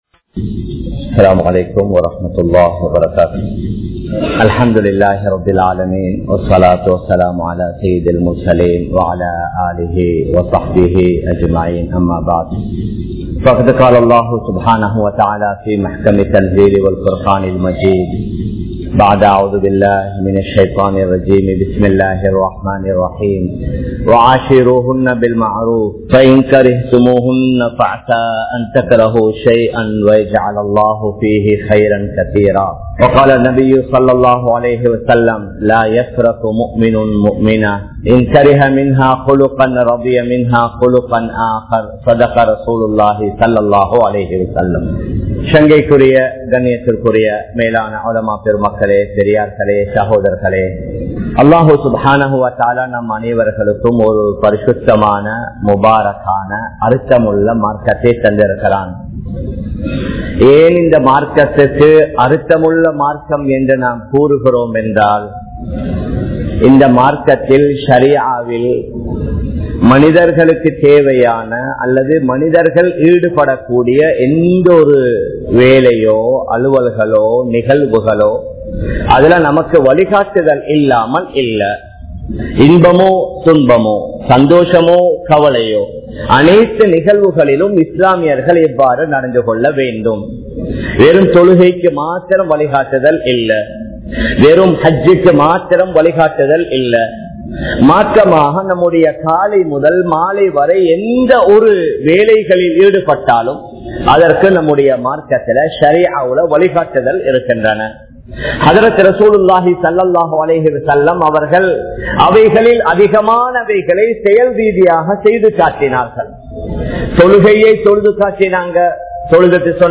Kanavan Manaivien Pirachinaihal (கணவன் மனைவியின் பிரச்சினைகள்) | Audio Bayans | All Ceylon Muslim Youth Community | Addalaichenai